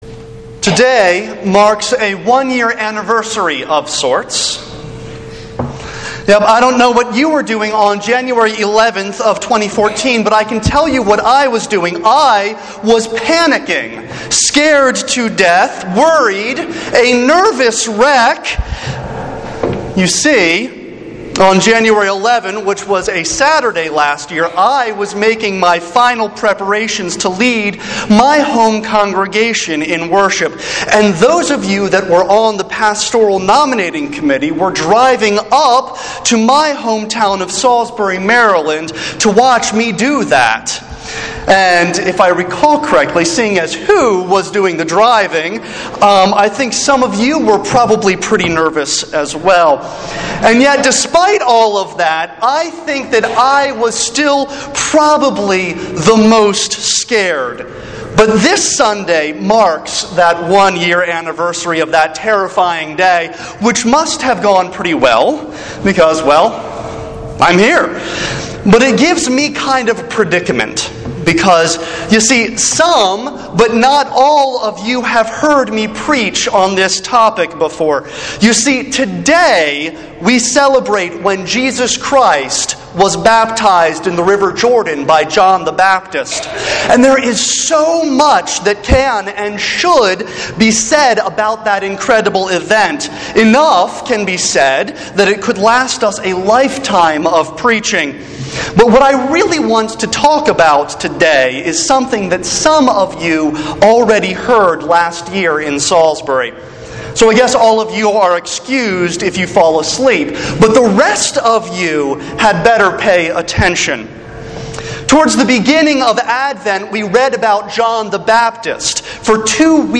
Passage: Psalm 29; Genesis 1:1-5; Acts 19:1-7; Mark 1:4-11 Service Type: Sunday Worship